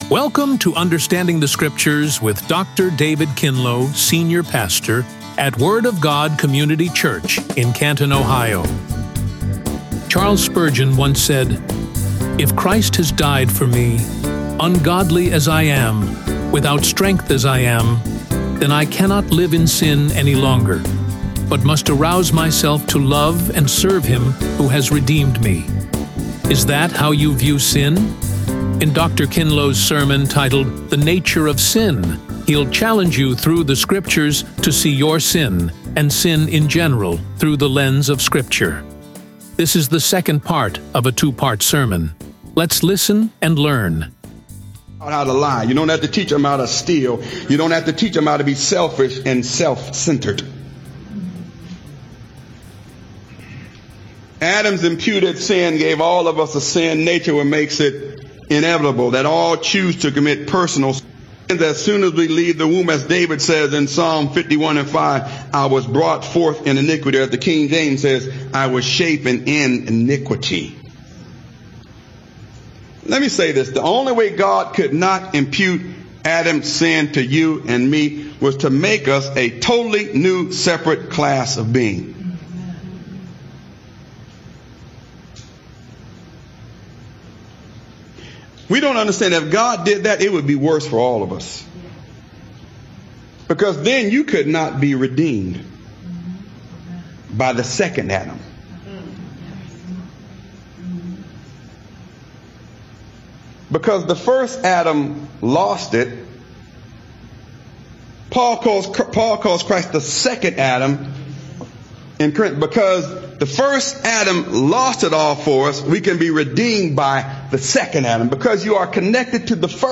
RADIO SERMON